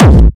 Kick 7.wav